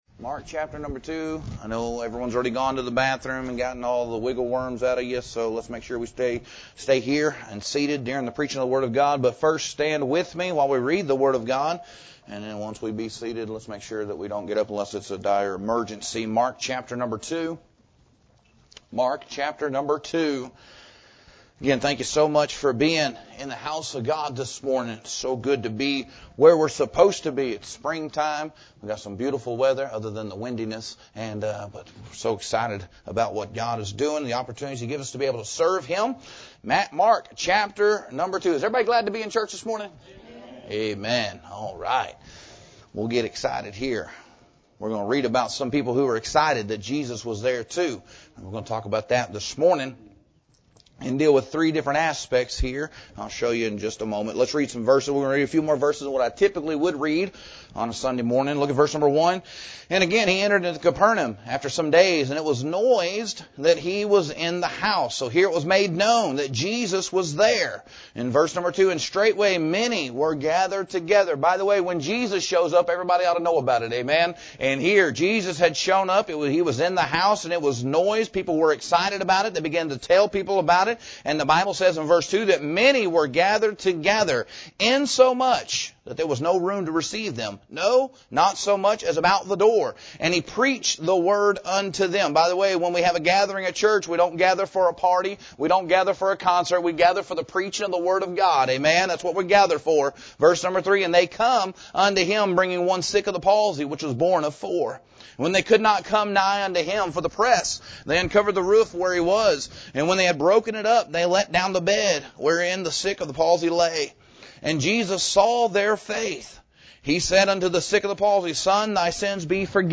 Introduction Sermon Title: “Which One Are You?”